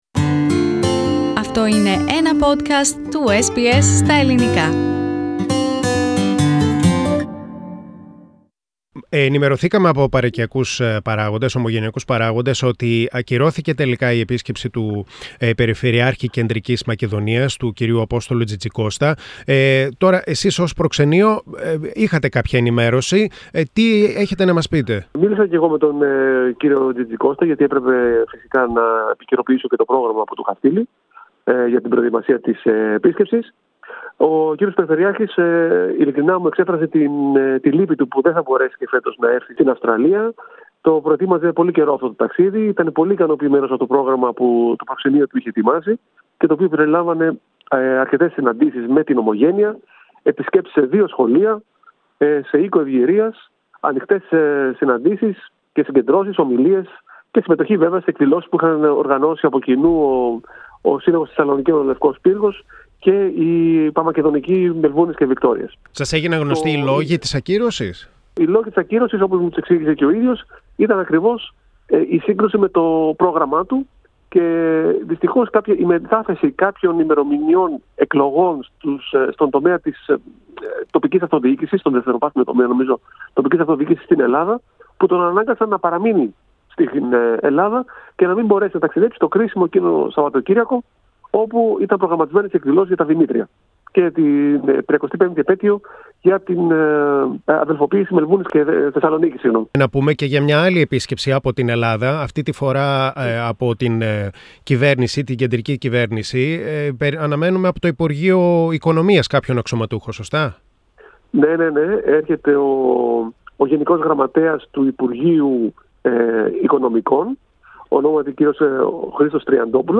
Central Macedonia governor Apostolos Tzitzikostascancels his visit to Australia but the Greek Finance Ministry's general secretary Christos Triantopoulos is on his way down Under. General-Consul of Greece in Melbourne Dimitrios Michalopoulos talks to SBS Greek.